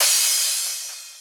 cym_double.ogg